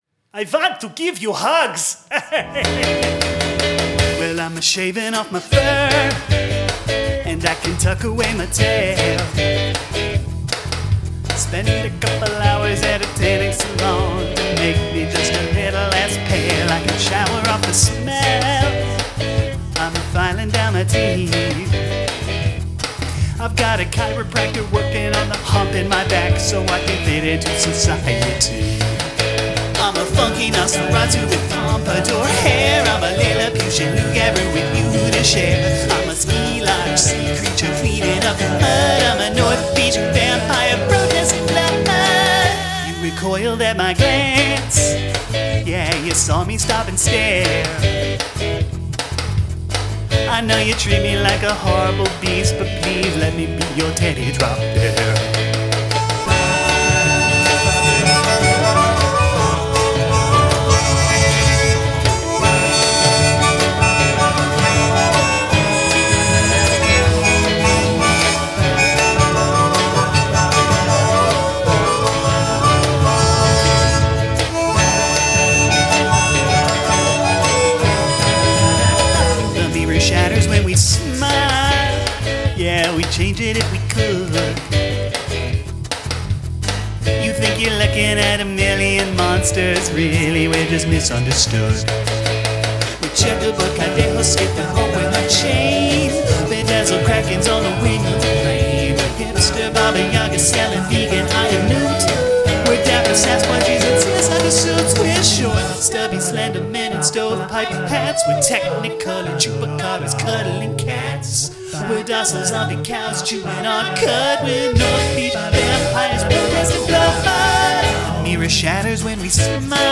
Include an a cappella section
I like the slow-down at the end.